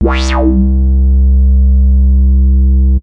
15Bass12.WAV